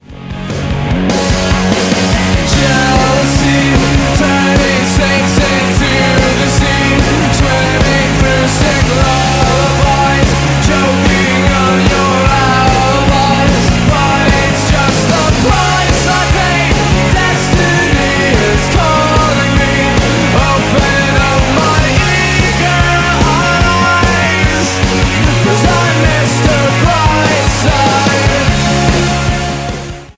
This is a sound sample from a commercial recording.